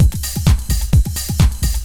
OSH Jive Beat 1_129.wav